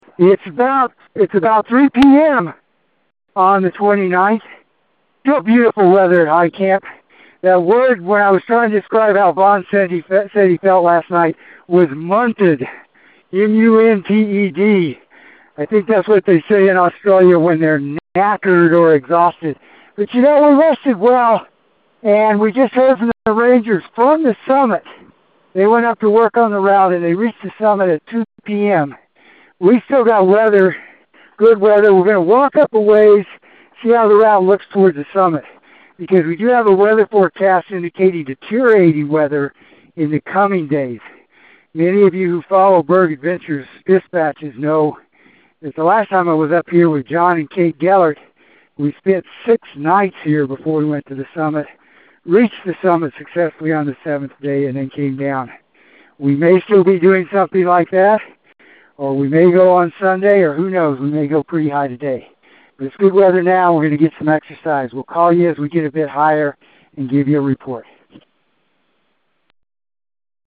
Expedition Dispatch